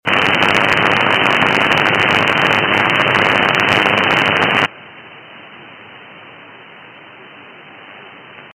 Она появлялась плавно, как бы прогреваясь, с каким-то щёлкающим бурлением и через пару минут забивала даже местную вещательную 50 кВт станцию, которая всего в 10 км от меня.
Вот, послушайте какая это к счастью уже БЫЛА гадость на 3600. Пойман на запись момент отключения:
80m_SSB_stop_crop.mp3